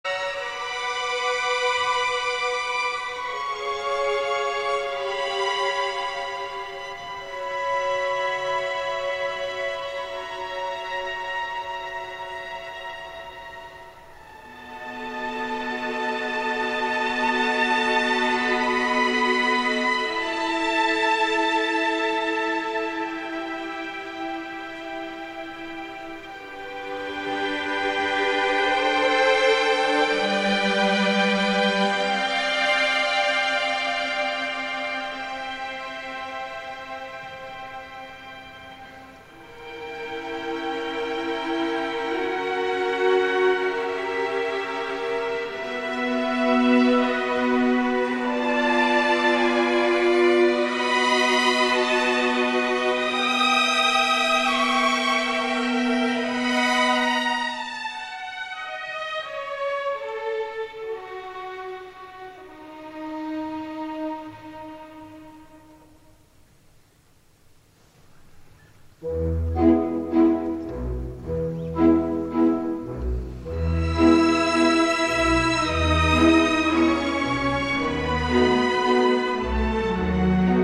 Here's a 1 minute sample in the original format (44.1 KHz, stereo, 128 kbps):